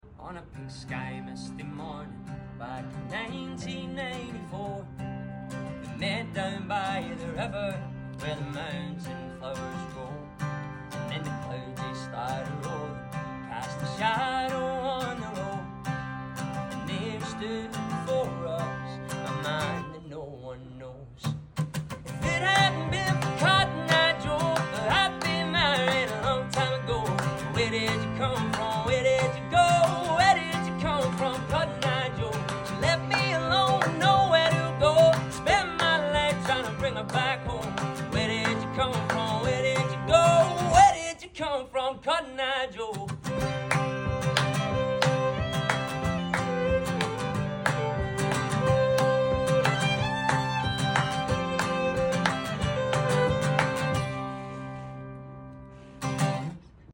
Scottish Folk 🤝🏼 Country Music